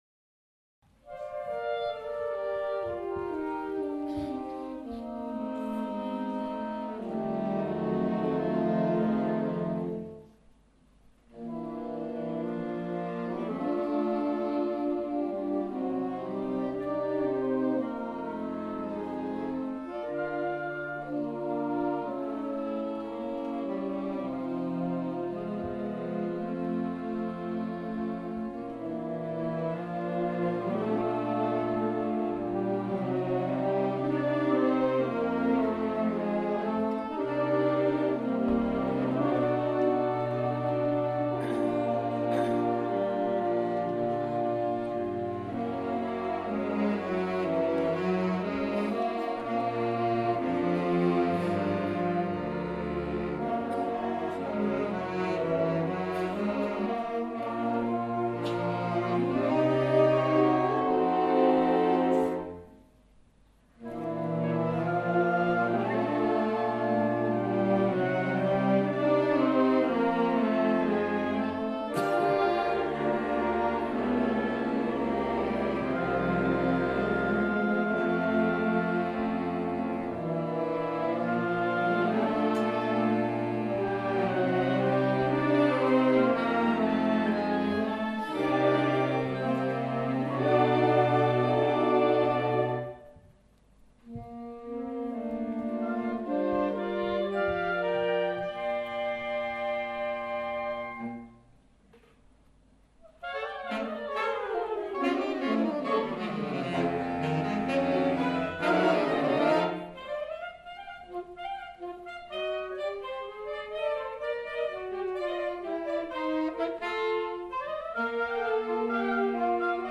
Performed by the amazing Saxidentals, our saxophone ensemble